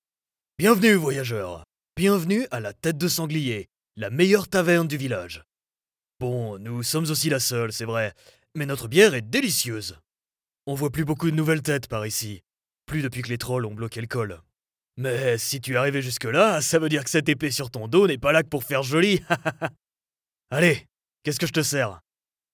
落ち着いた／穏やか
やさしい
知的／クール
多言語対応で汎用性が高く、温かく親しみやすい、プロフェッショナルで信頼できる声をお届けします。
収録　　宅録、
【フランス語ボイスサンプル】
Voice Actor Sample8（宿屋の主人）[↓DOWNLOAD]